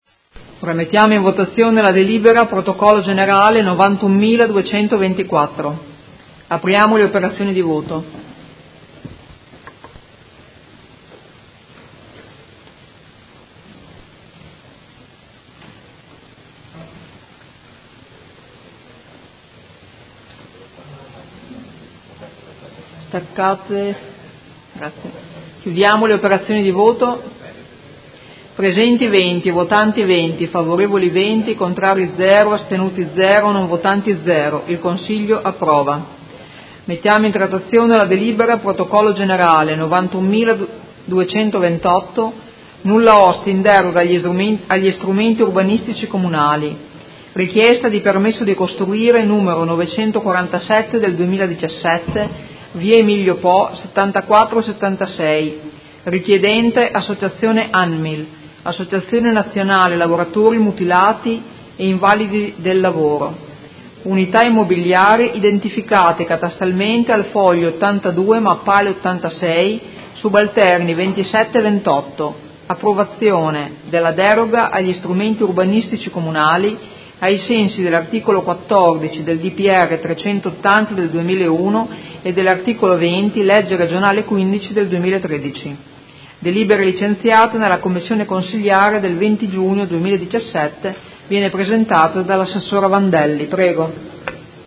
Presidentessa — Sito Audio Consiglio Comunale
Presidentessa